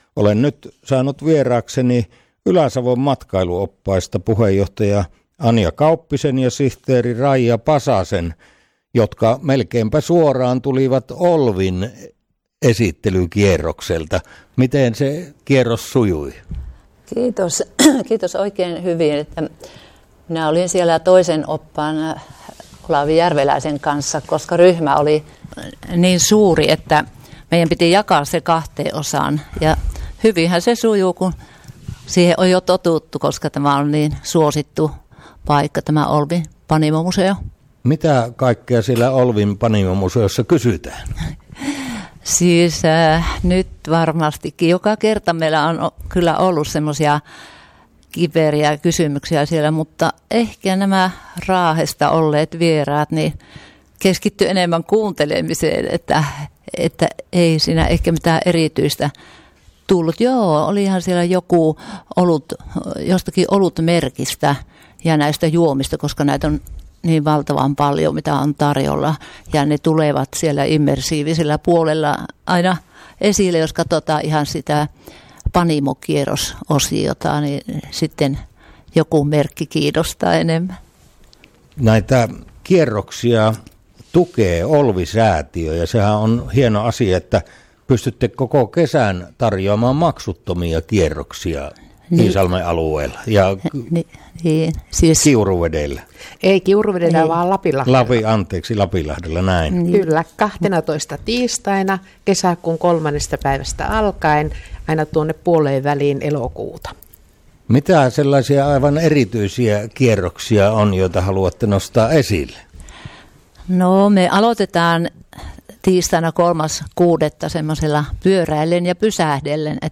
Oppaiden kesätoiminnan haastattelu Radio Sandelsissa 5.5.2025